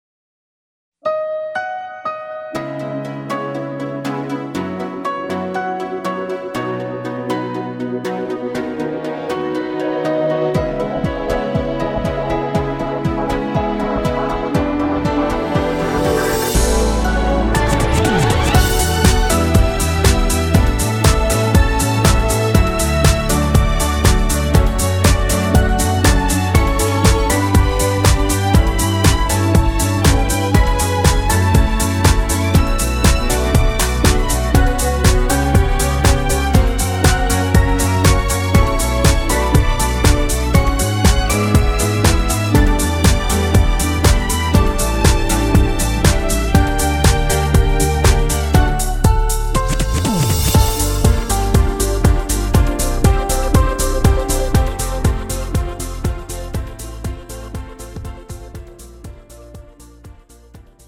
음정 (-1키)
장르 가요